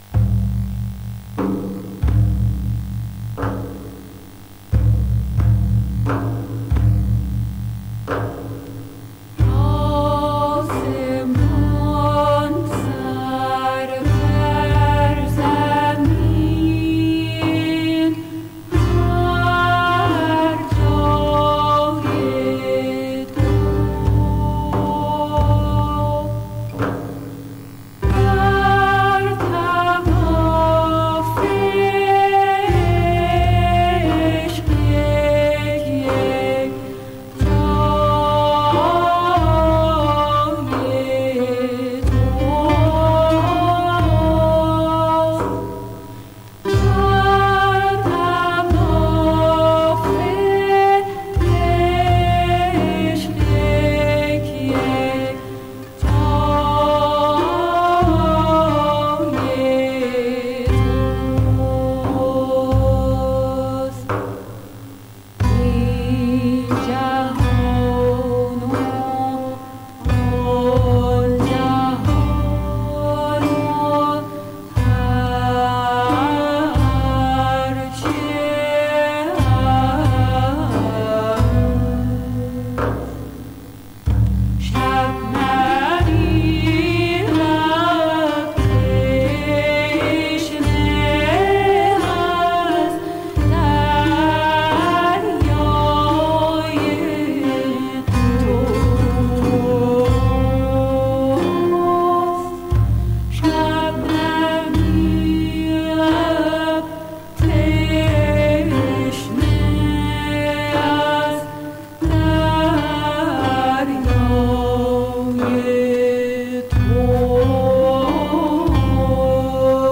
ヴィオラ×声！！ペルシャの伝統音楽とガムラン～ライヒ的なミニマリズムからの影響が感じられる大傑作！！
※レコードの試聴はノイズが入ります。